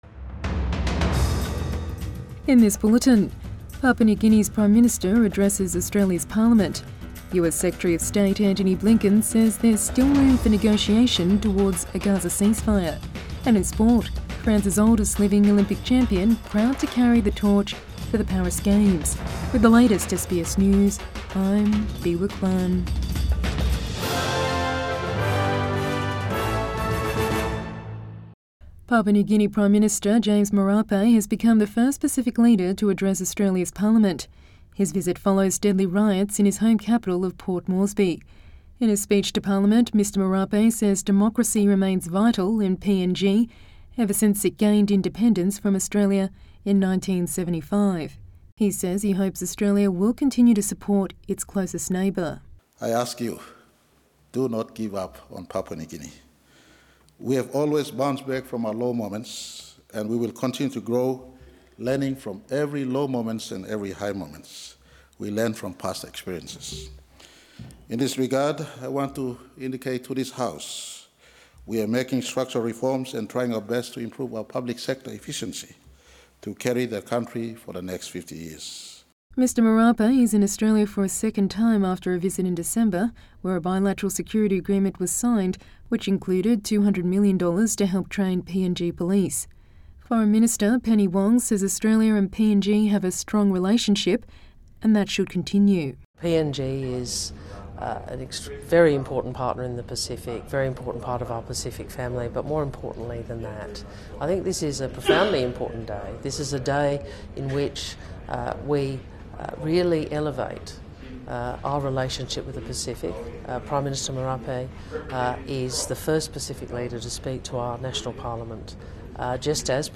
Midday News Bulletin 8 February 2024